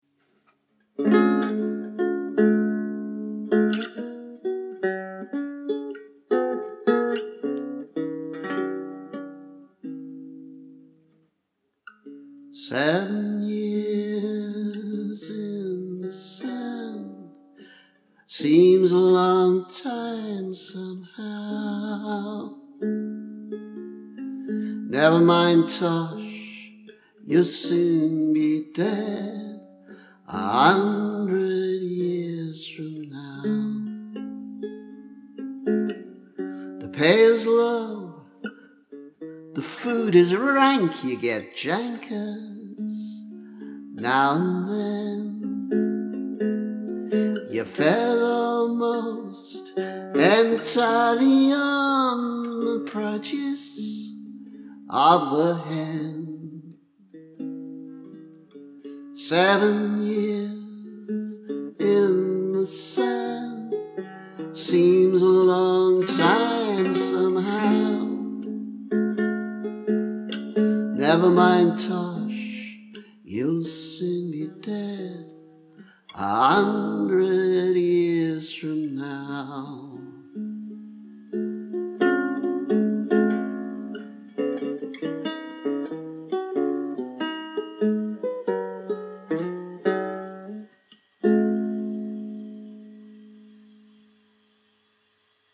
I’ve posted a version of this here before, but I think I prefer this less ambitious and better executed guitarlele version.
According to Ewan MacColl, from whose singing I learned this many years ago, this doleful World War II song was originally “the anthem of the Middle East air force regiment” but was adopted by many units that saw service in the region.